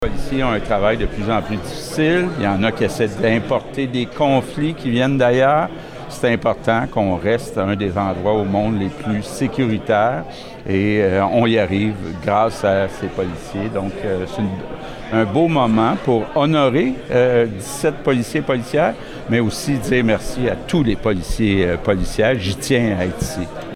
Le premier ministre du Québec, François Legault a participé, lundi après-midi, aux célébrations de la Journée de reconnaissance policière à l’École de police de Nicolet.